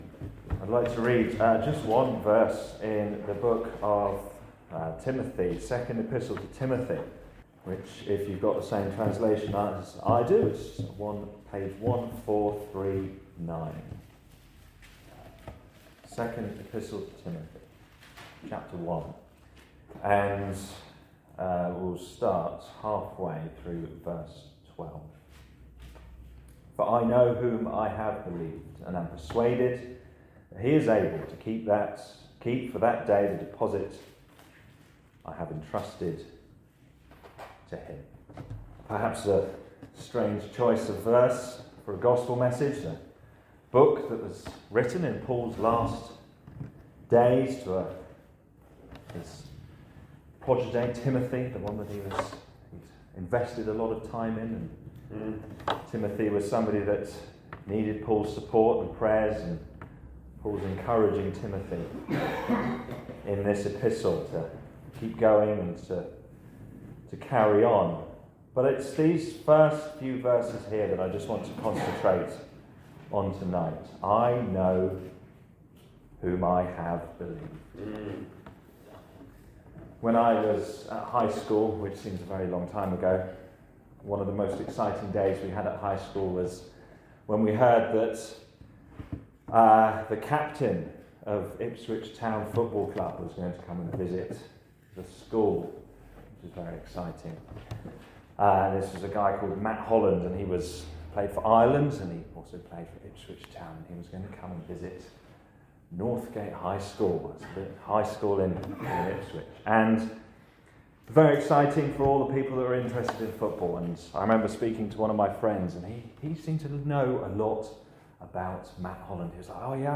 This gospel preaching presents a clear message of assurance and trust in the Lord Jesus Christ. It speaks of the confidence a believer can have in entrusting their soul to a faithful Saviour—one who saves, keeps, and secures for eternity.